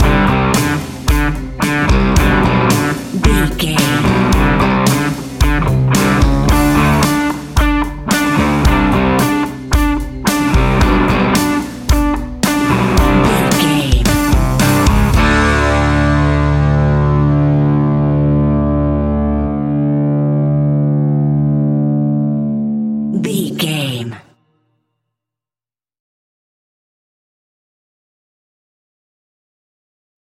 Ionian/Major
D
energetic
driving
aggressive
electric guitar
bass guitar
drums
hard rock
heavy metal
distorted guitars
hammond organ